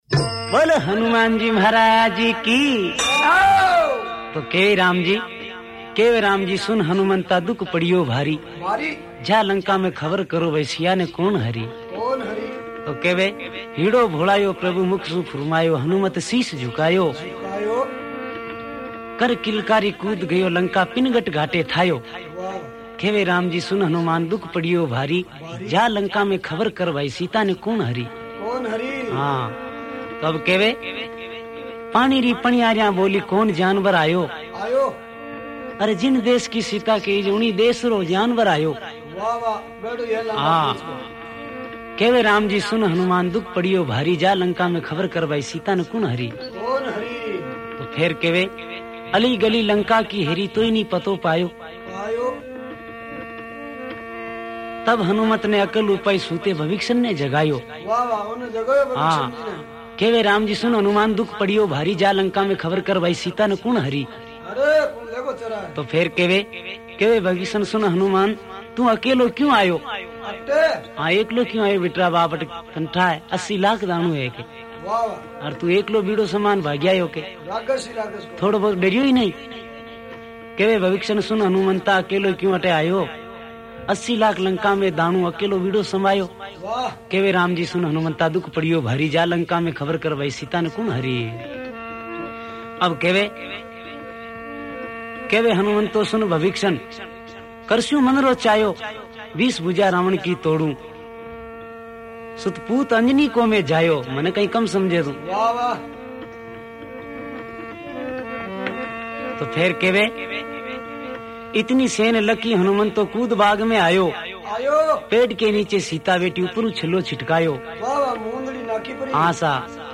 Rajasthani Songs
Hanuman Ji Ke Bhajan